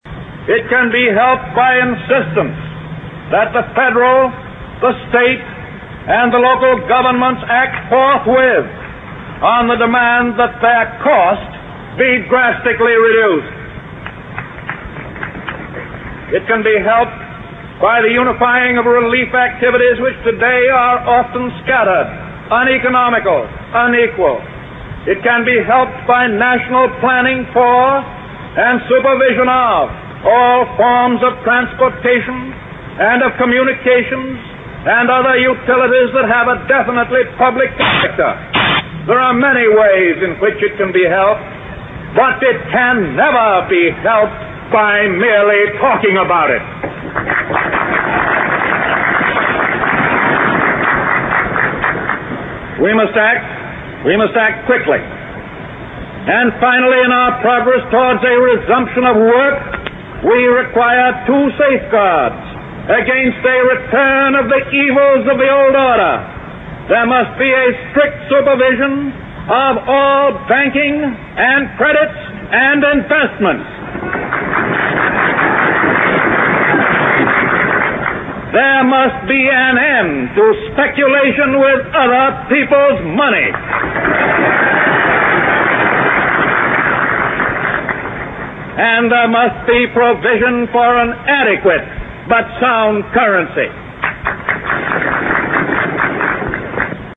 名人励志英语演讲 第21期:我们唯一害怕的是害怕本身(6) 听力文件下载—在线英语听力室